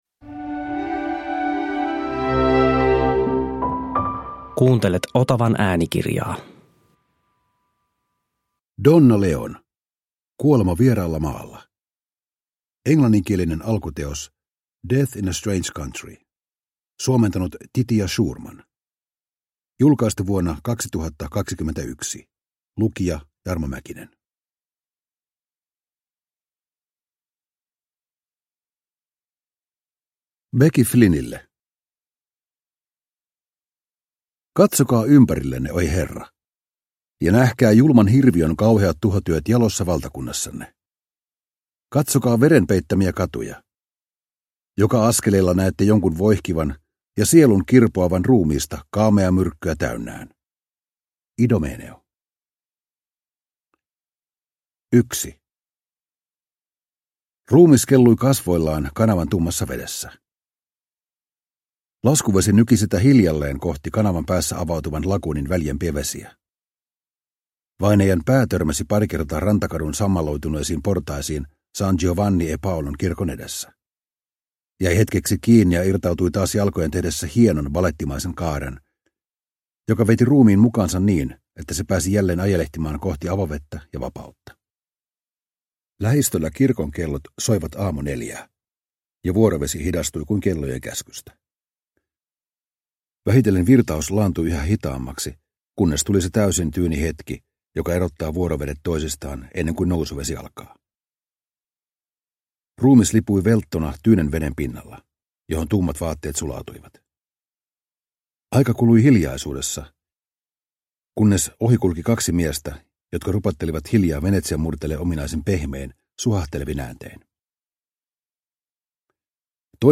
Kuolema vieraalla maalla – Ljudbok – Laddas ner